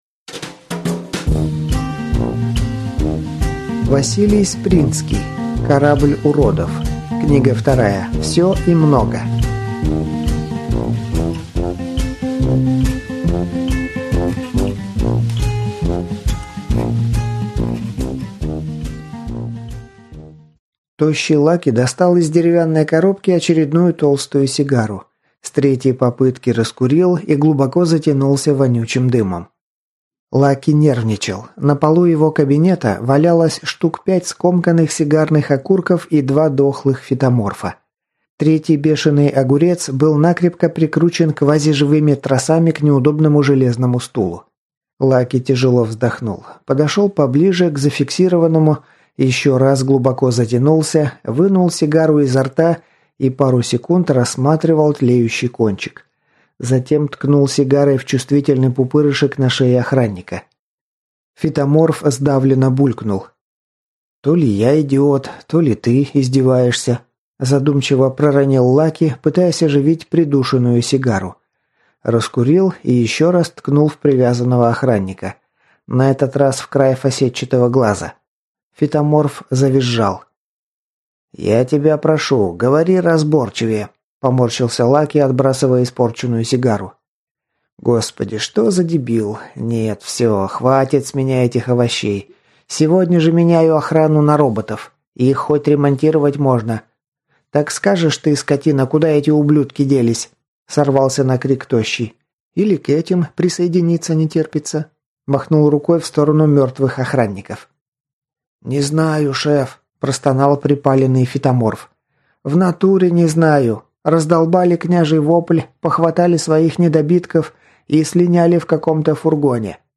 Аудиокнига Всё и много | Библиотека аудиокниг